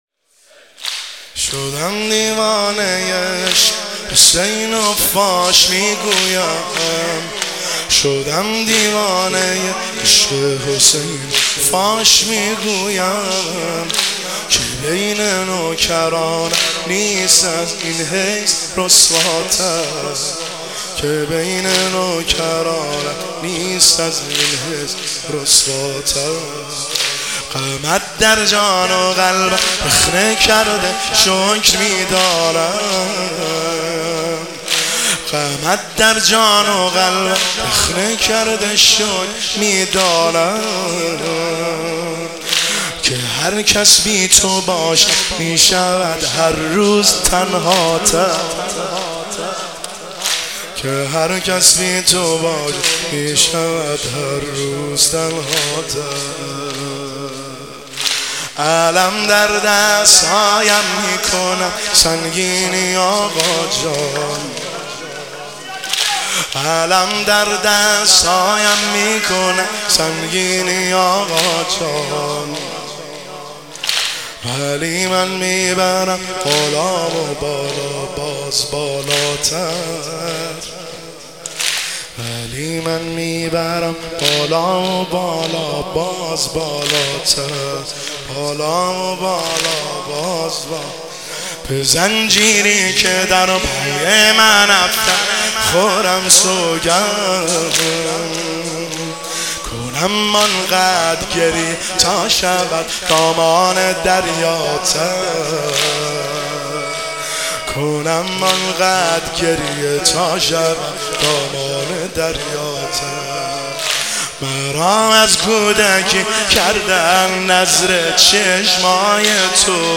واحد  شب دوم محرم الحرام 1404